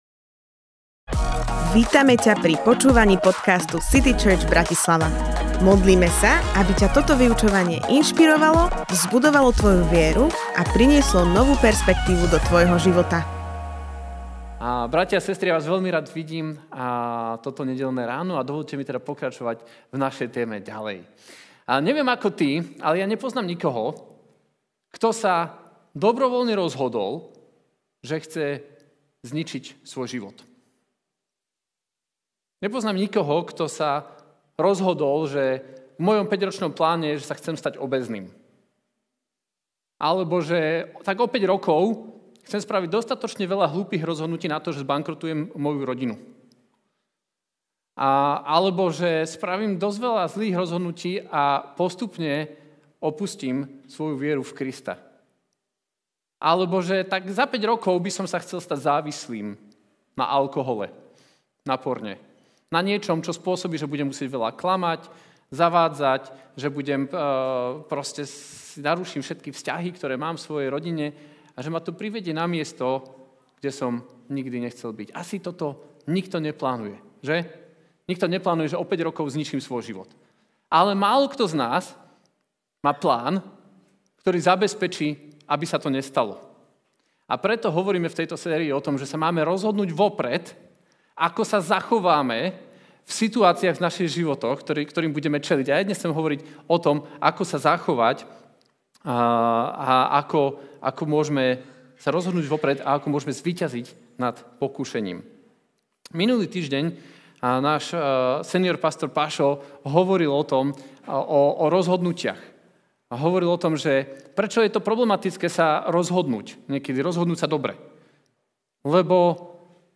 Kázeň týždňa Zo série kázní